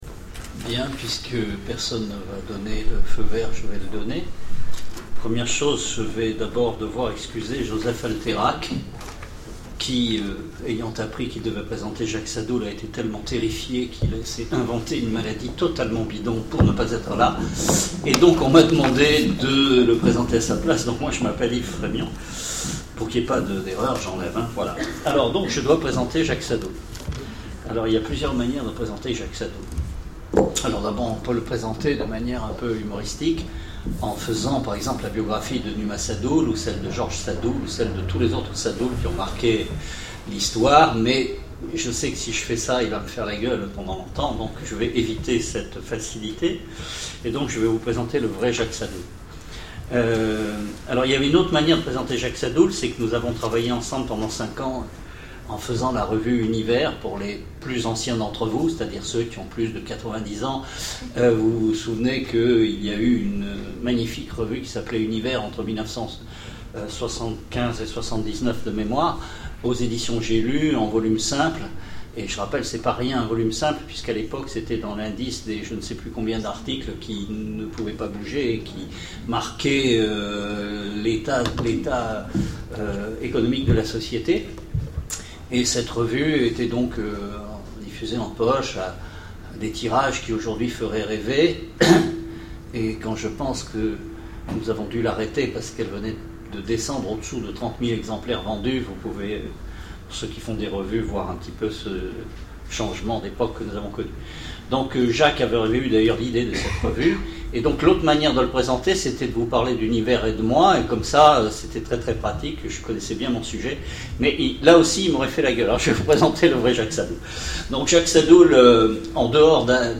Rencontres de l'Imaginaire de Sèvres 2011 : Conférence avec Jacques Sadoul Télécharger le MP3 à lire aussi Jacques Sadoul Frémion Genres / Mots-clés Rencontre avec un auteur Conférence Partager cet article